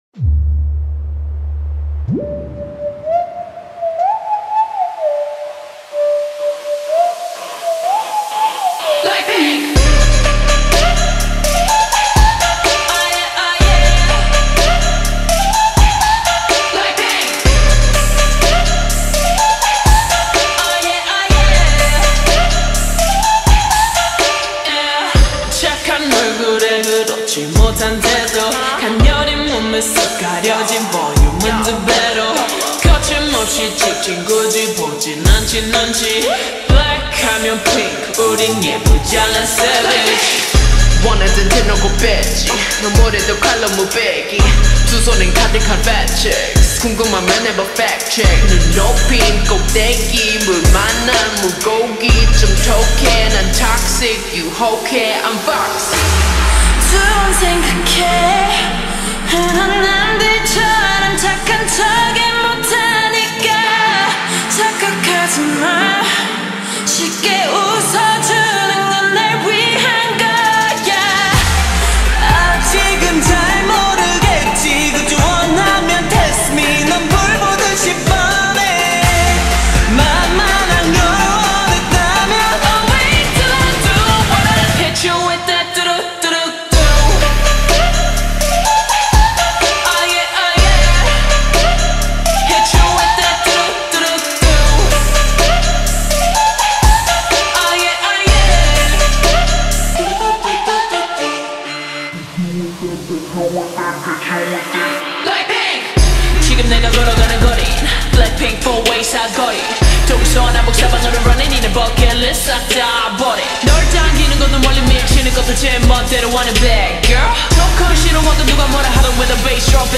نسخه آهسته کاهش سرعت